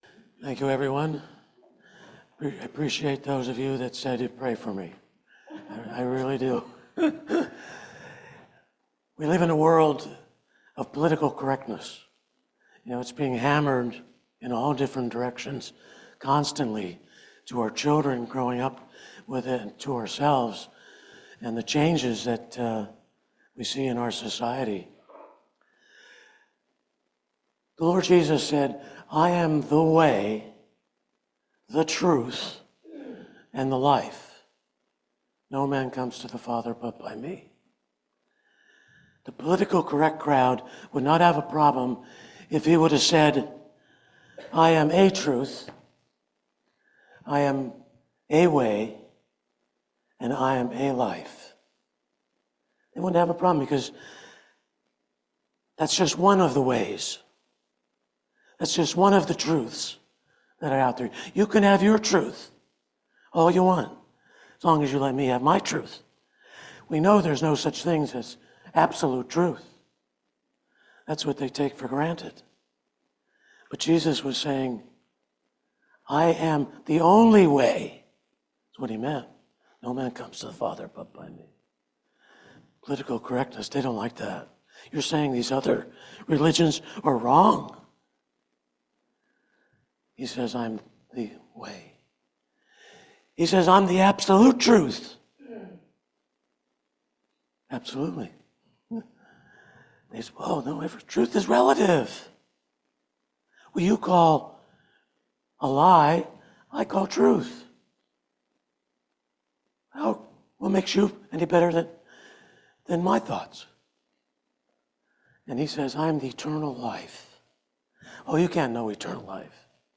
Titus 1:12 Service Type: Family Bible Hour Sound teaching and sober living makes Christ attractive in a corrupt society.